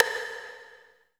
34 V.STICK-L.wav